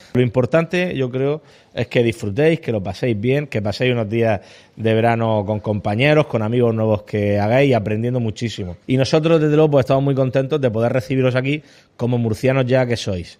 Declaraciones del presidente del Gobierno regional, Fernando López Miras, dirigiéndose a los niños afectados de hemofilia que, procedentes de 13 provincias, participan en el campamento de verano de La Charca (Totana).